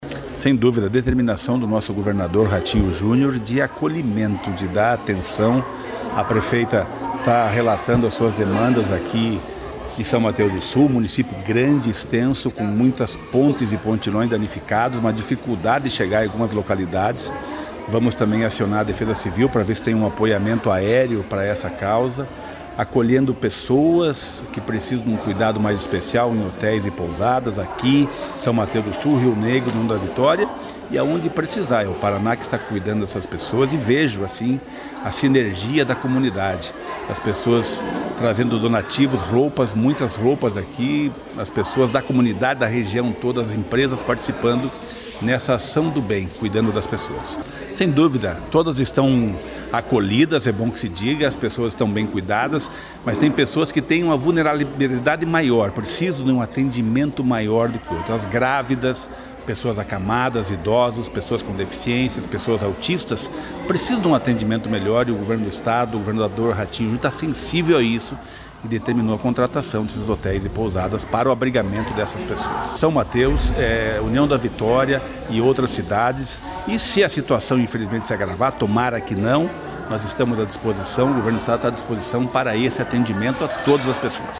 Sonora do secretário Estadual de Desenvolvimento Social e Família, Rogério Carboni, sobre o atendimento aos desabrigados pelas chuvas no Paraná deste mês